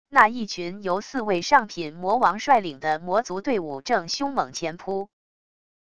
那一群由四位上品魔王率领的魔族队伍正凶猛前扑wav音频生成系统WAV Audio Player